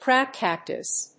アクセントcráb càctus